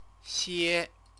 chinese_characters_xie-2_xie-2.mp3